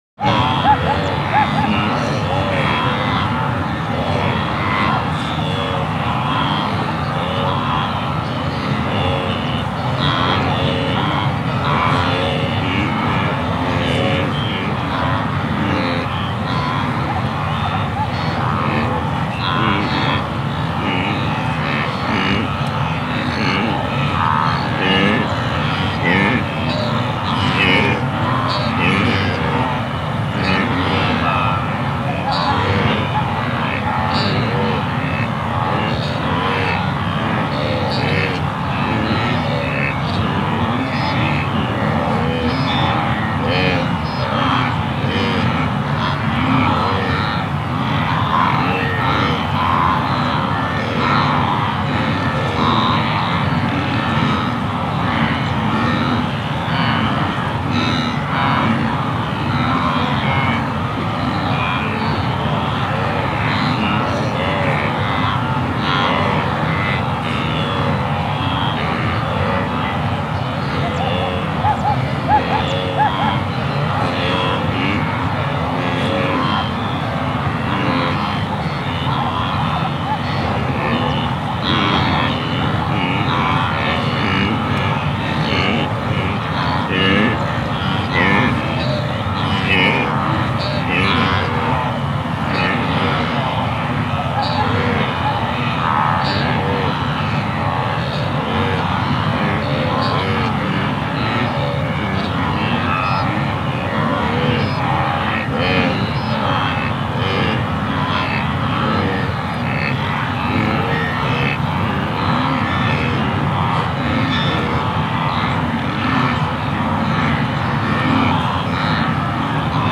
جلوه های صوتی
دانلود صدای گاو وحشی 10 از ساعد نیوز با لینک مستقیم و کیفیت بالا
برچسب: دانلود آهنگ های افکت صوتی انسان و موجودات زنده دانلود آلبوم صدای حیوانات وحشی از افکت صوتی انسان و موجودات زنده